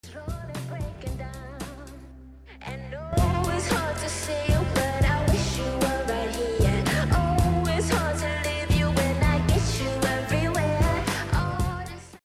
soft edit audio